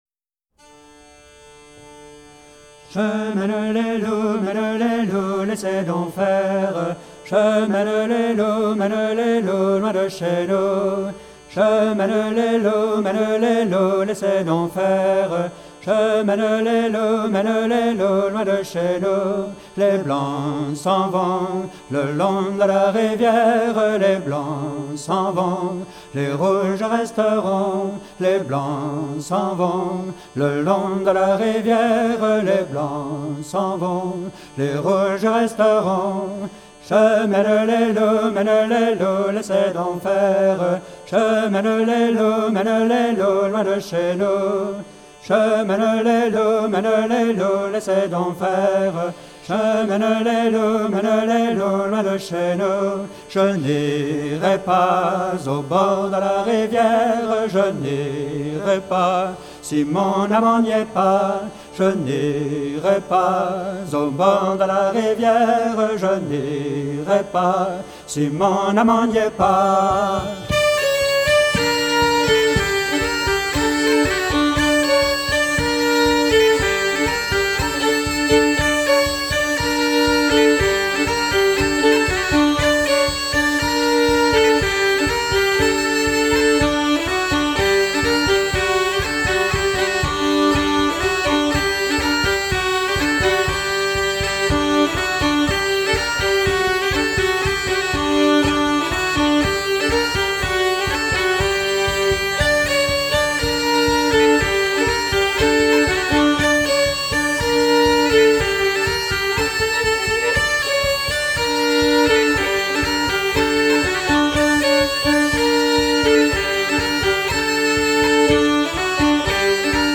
Bourrée 2 temps “Je mène les loups” (Atelier de Danse Populaire)